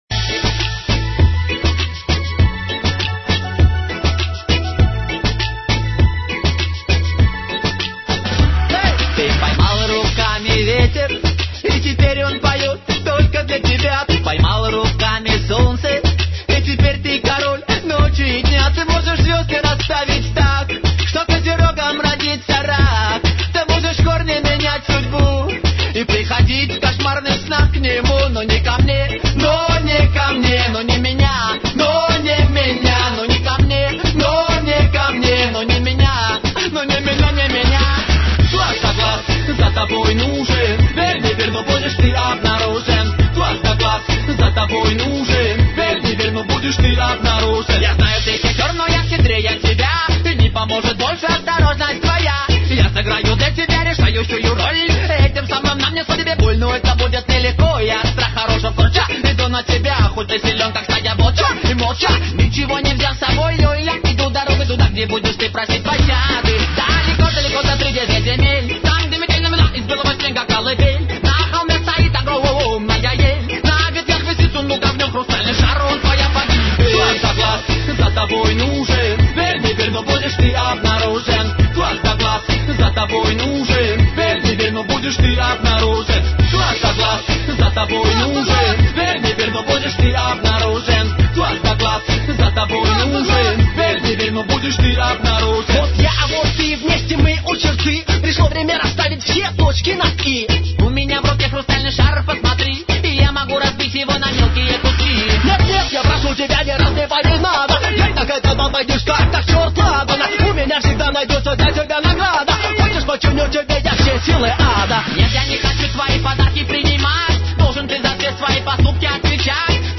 песенка веселая! поднимает настроение...